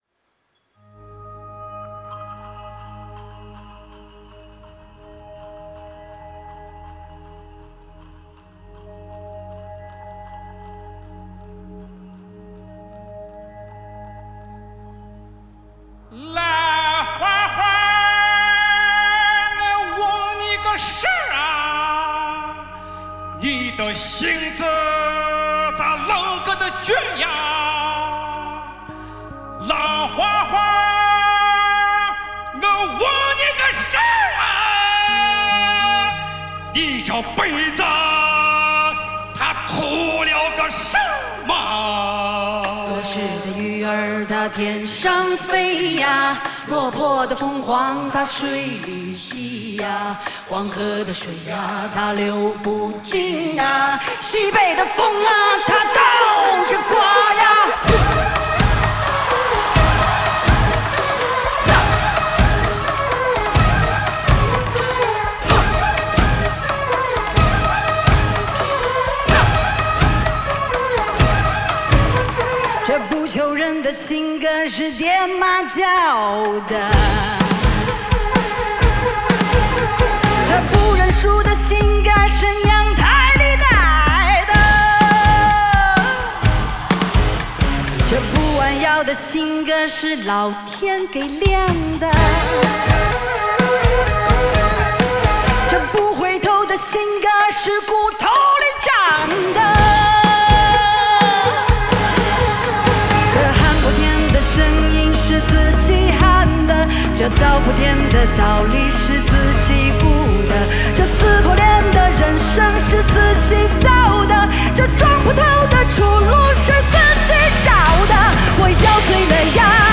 Ps：在线试听为压缩音质节选，体验无损音质请下载完整版
Live|典藏
吉他
贝斯
鼓手
键盘
二胡
中音板胡
高音板胡
原生态助唱